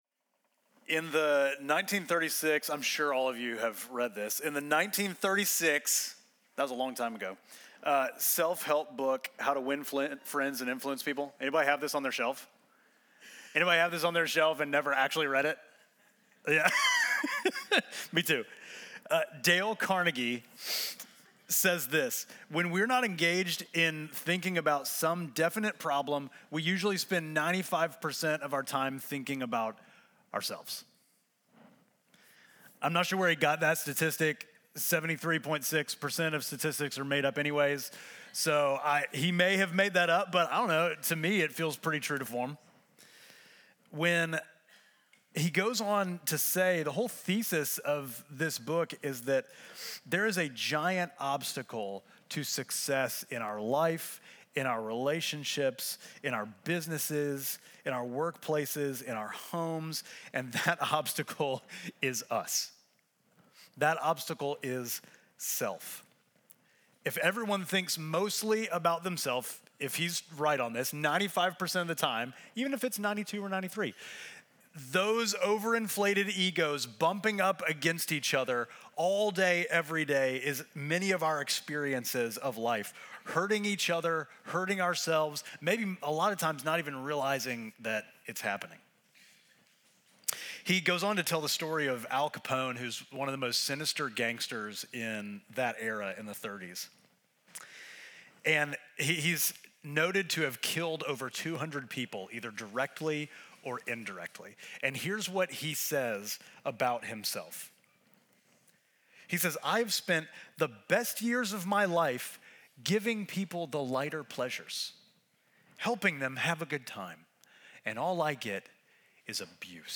Midtown Fellowship Crieve Hall Sermons The Subtraction of Division Feb 23 2025 | 00:38:56 1x 00:00 / 00:38:56 Subscribe Share Apple Podcasts Spotify Overcast RSS Feed Share Link Embed